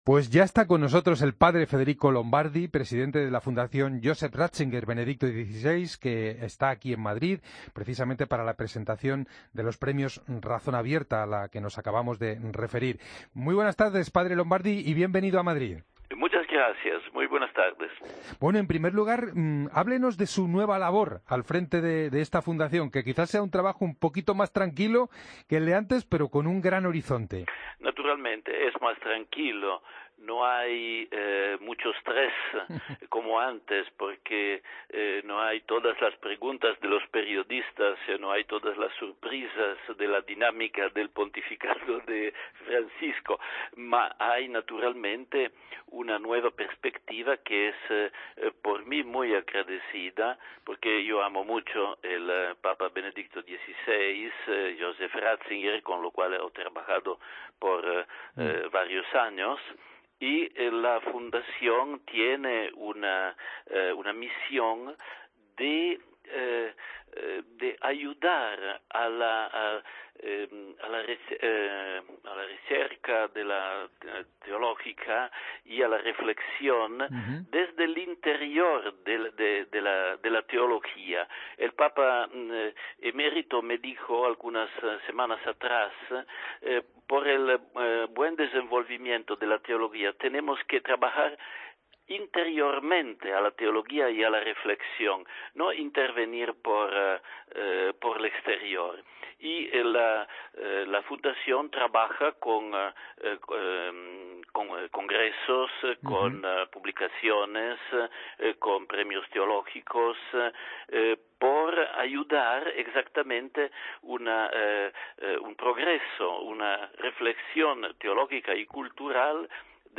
Escucha la entrevista al padre Federico Lombardi en 'El Espejo'